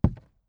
ES_Walk Wood Creaks 18.wav